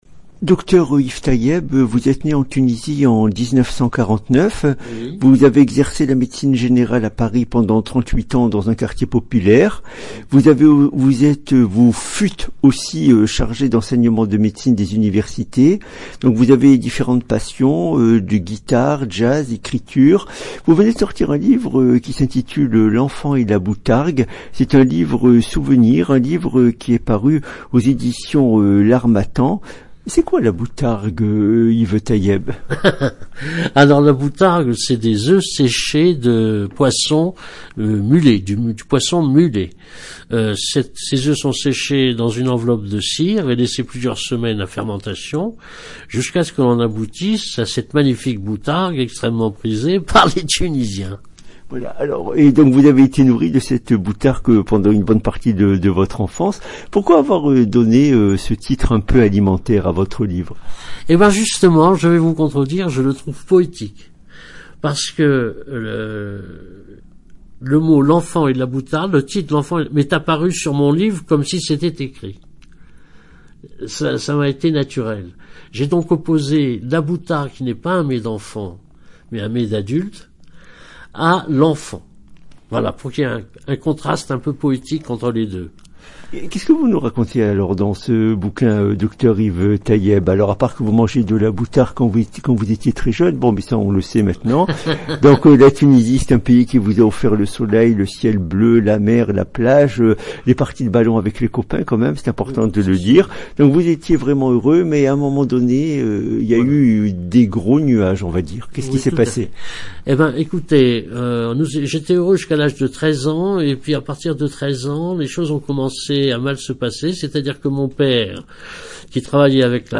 Émission Radio